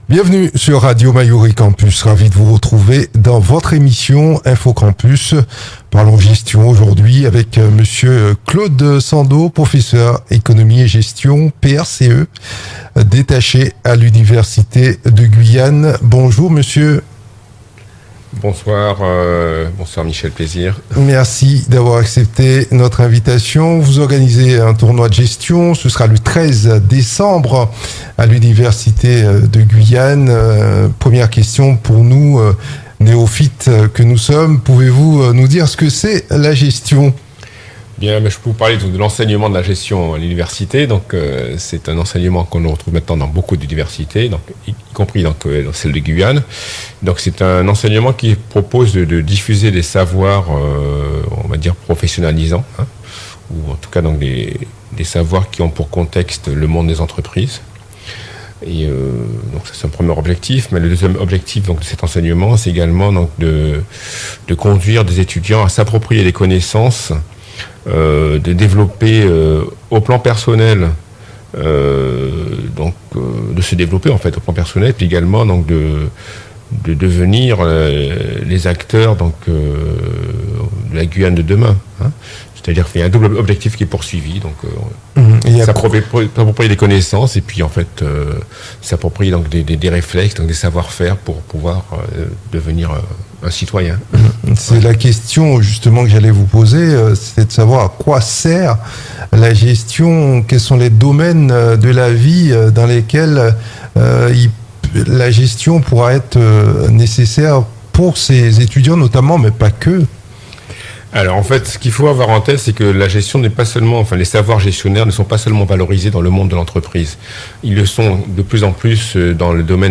Les français sont-ils aussi mauvais en gestion? un professeur de gestion nous livre son analyse.